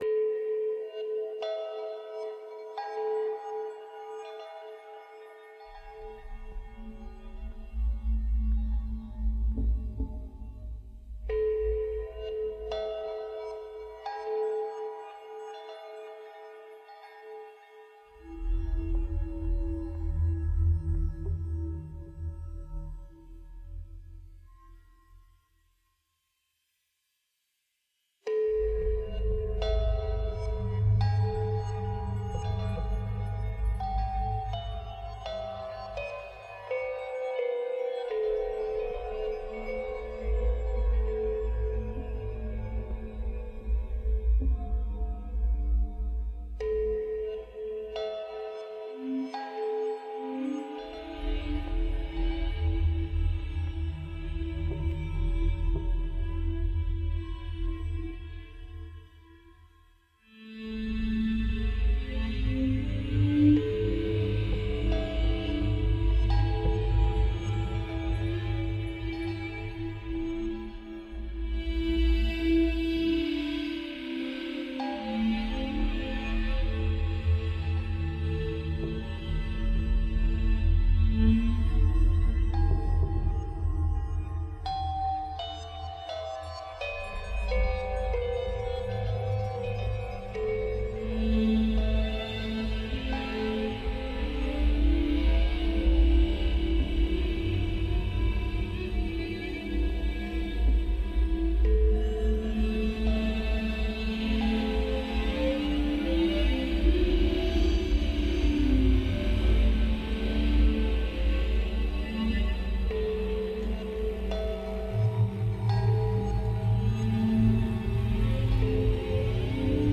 Dubby, hypnotic ambient with a sense of hope and being
Ambient , Experimental , Dub